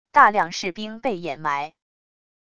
大量士兵被掩埋wav音频